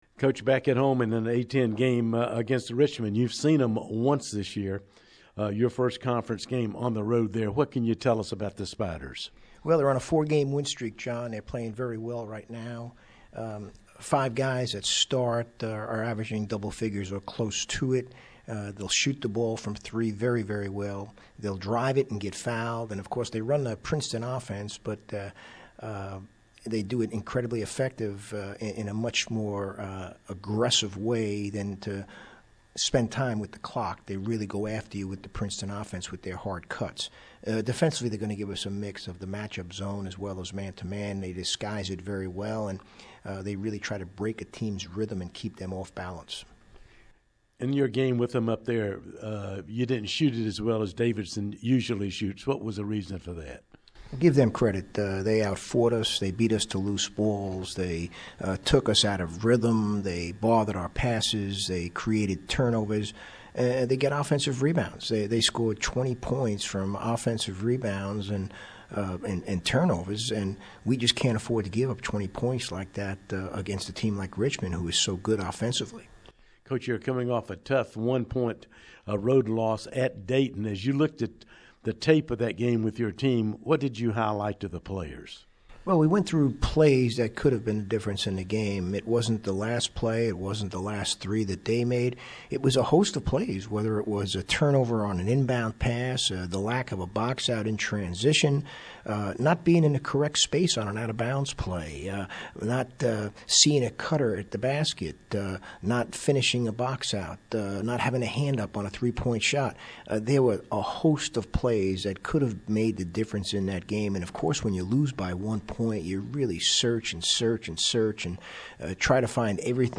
Pregame Interview
HomeRichmondPregame.MP3